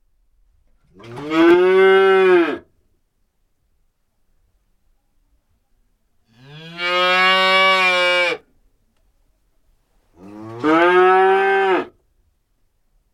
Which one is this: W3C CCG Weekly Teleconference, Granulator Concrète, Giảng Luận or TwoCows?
TwoCows